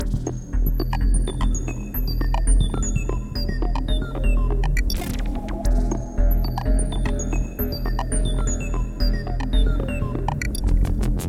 描述：女人的声音用英式英语。一些无线电噪音。
Tag: 讲话 谈话 语音 英语 女性 电台 英国 特温特 大学 女人